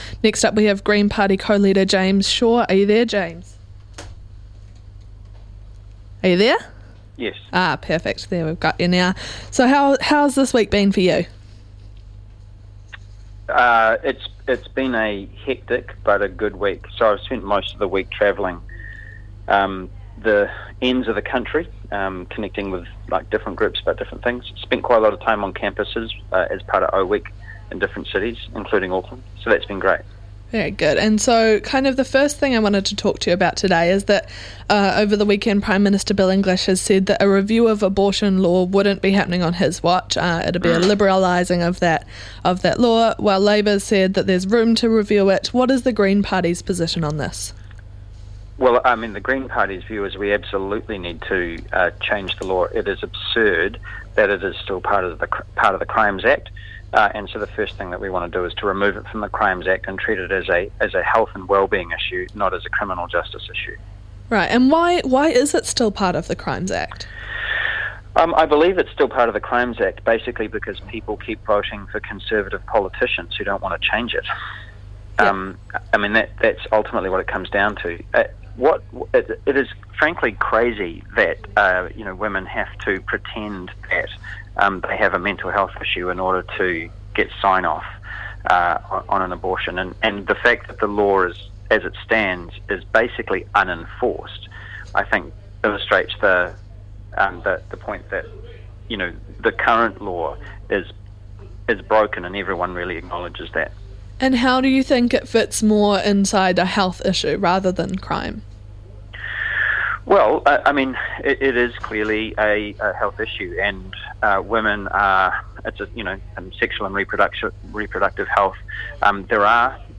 speaks to Green Party co-leader James Shaw about some of Prime Minister Bill English's comments over the past weekend regarding abortion law reform and potentially shortening jail sentences for prisoners who pass literacy programmes.